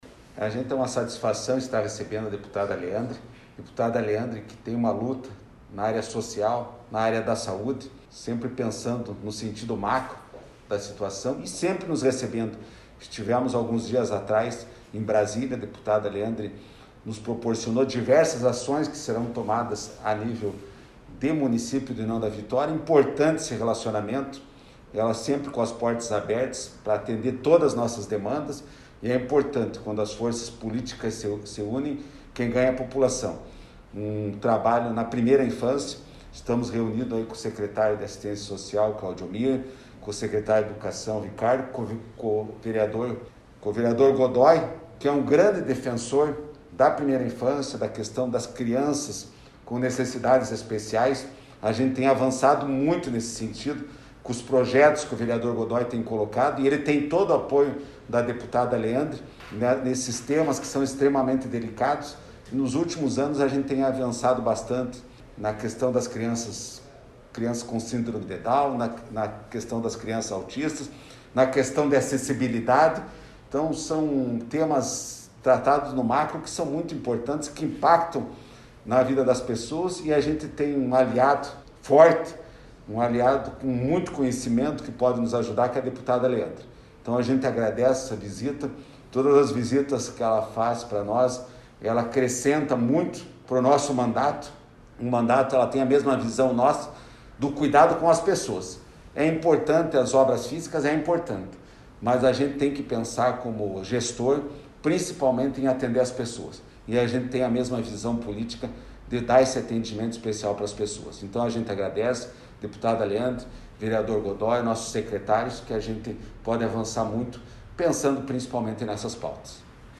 Acompanhe o áudio do prefeito abaixo: